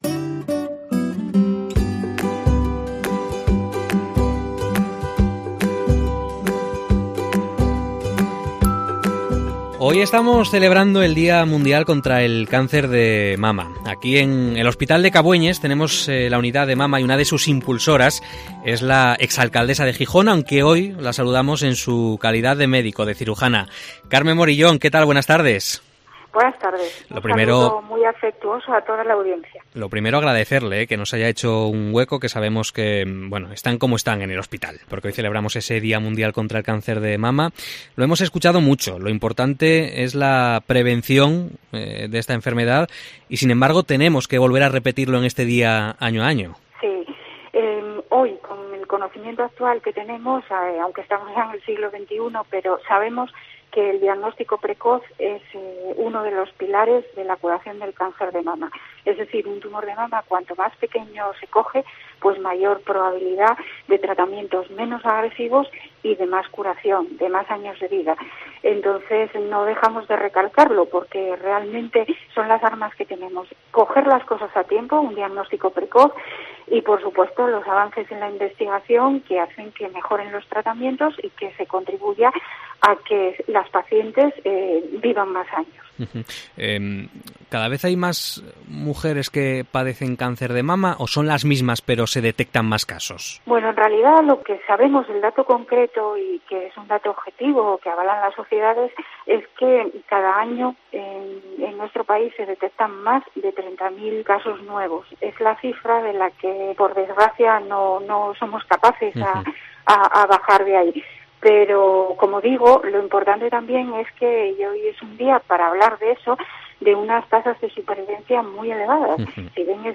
Entrevista a Carmen Moriyón en el Día Internacional contra el Cáncer de Mama